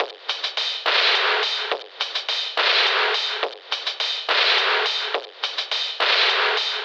DDW4 LOOP 4.wav